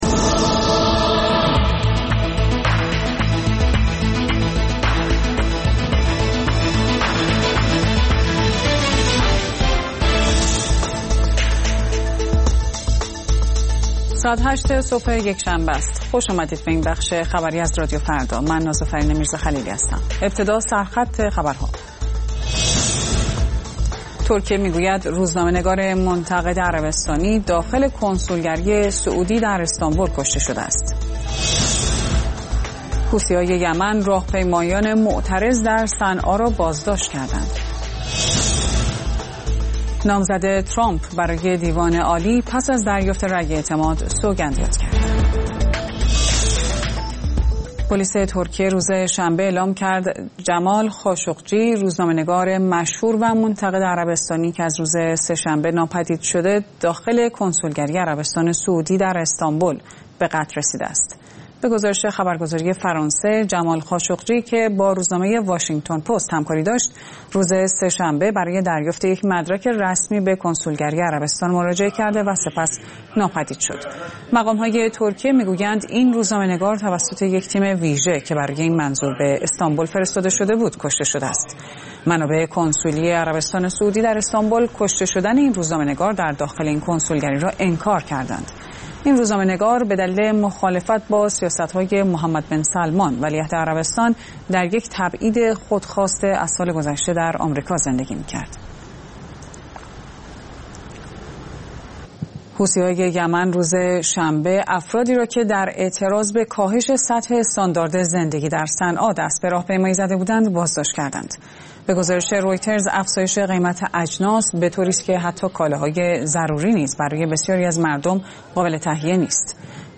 اخبار رادیو فردا، ساعت ۸:۰۰